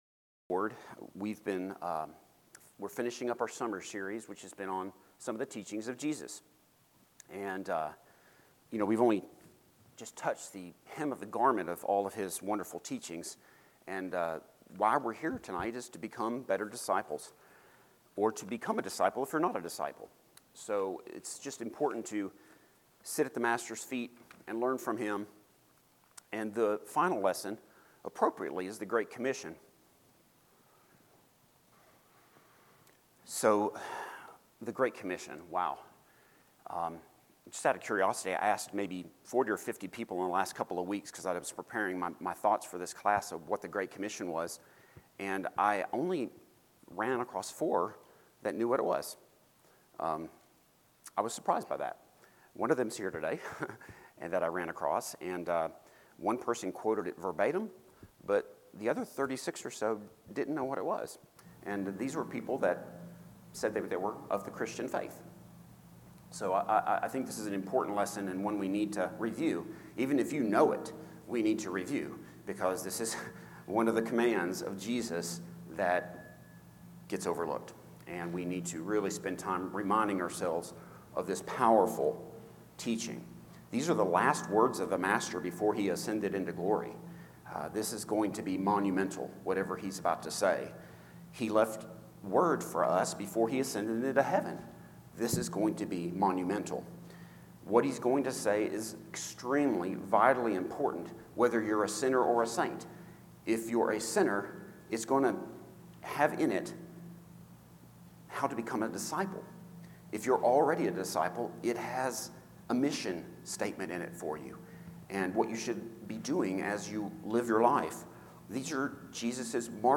Passage: Matthew 28:18-20, Mark 16:15-16 Service Type: Midweek Bible Class